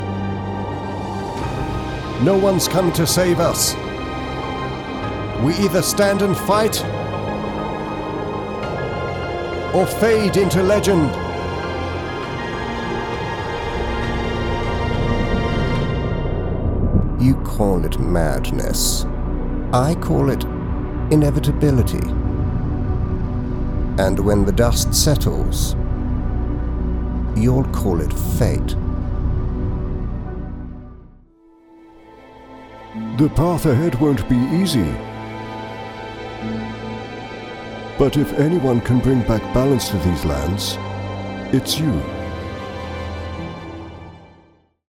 Authentic and native British speakers bring a professional, rich, refined and smooth sound to your next voice project.
Adult (30-50) | Older Sound (50+)